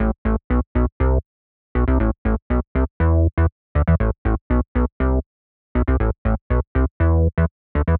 23 Bass PT3.wav